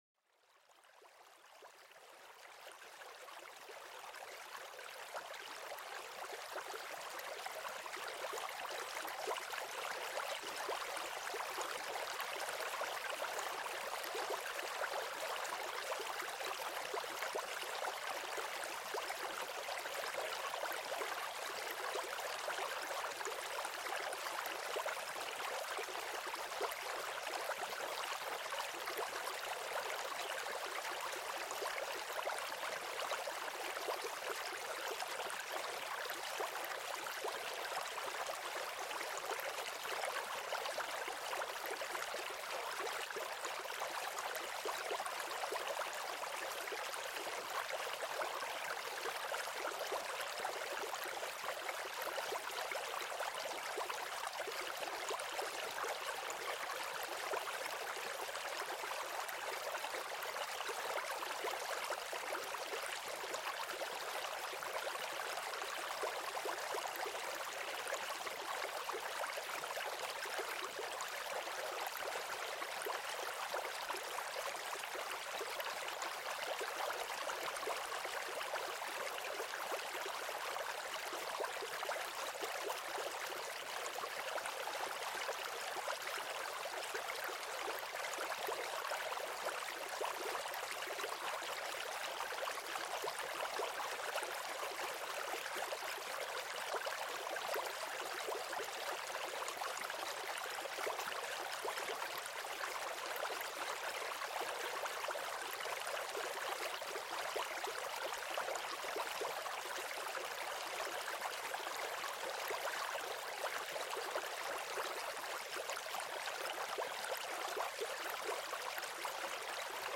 Plongez au cœur de la tranquillité avec le doux murmure d'un ruisseau serpentin.Laissez-vous bercer par le son cristallin de l'eau qui s'écoule, une véritable symphonie de la nature.Découvrez comment ce simple son peut réduire le stress et favoriser une relaxation profonde.À travers des enregistrements authentiques et immersifs, ce podcast vous invite à vous connecter aux merveilles de la nature pour une relaxation et un sommeil paisible.